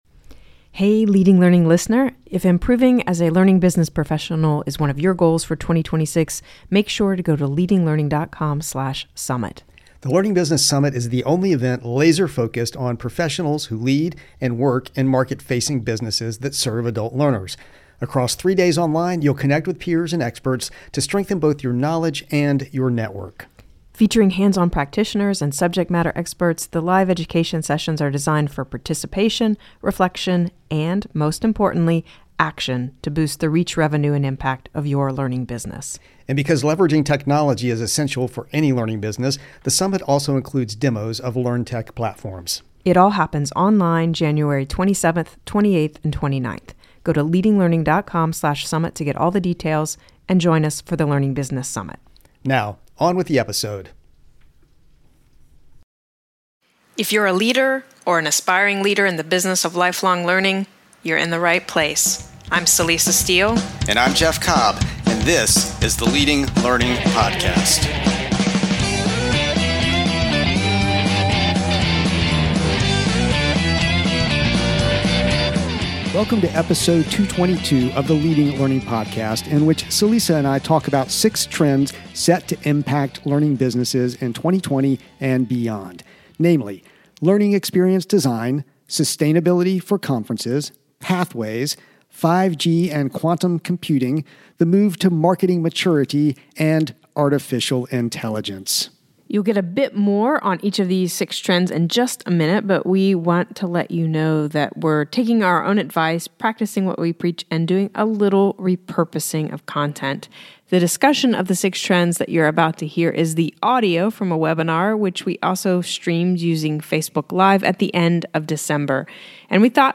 In this episode of the Leading Learning podcast, we are practicing what we preach by repurposing content from a recent Webinar and sharing six specific trends set to impact learning businesses in the new year, and beyond.